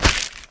STEPS Soft Plastic, Walk 03.wav